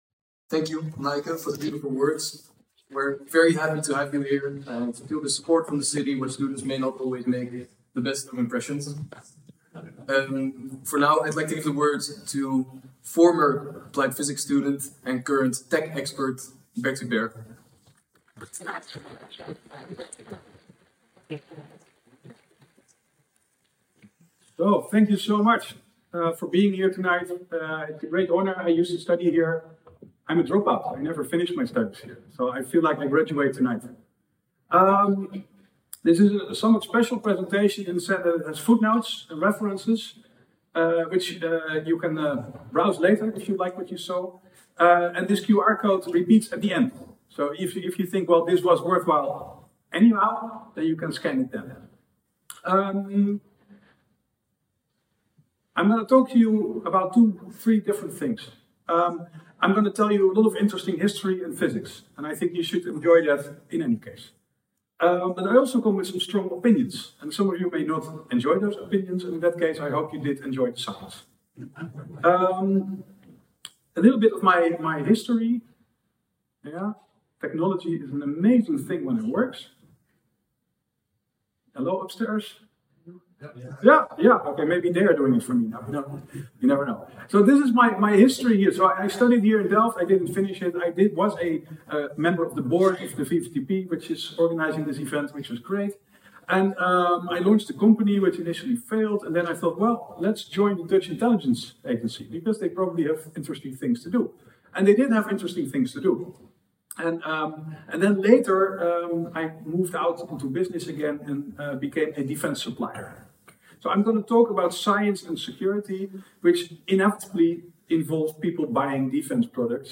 This is a mostly verbatim transcript of my lecture at the TU Delft VvTP Physics symposium “Security of Science” held on the 20th of November.
Also many thanks to the audio professional that helped clean up the recording.